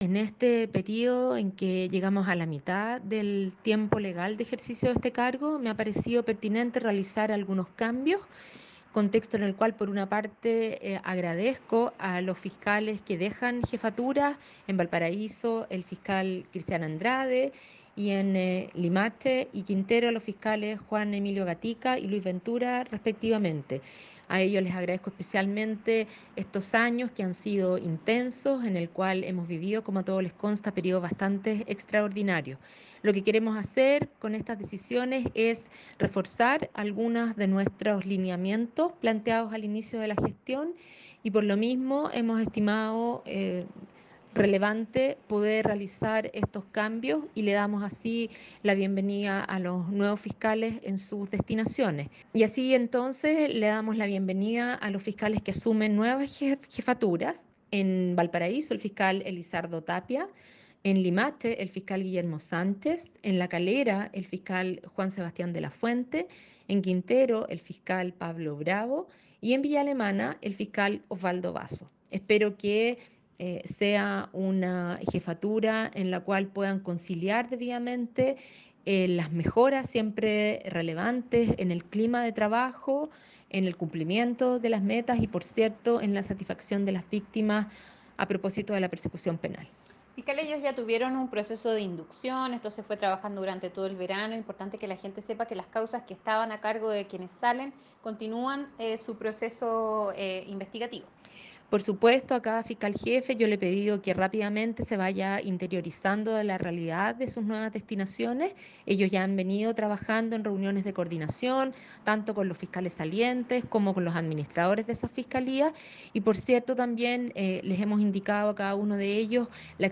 FISCAL-REGIONAL-VALPARAISO.mp3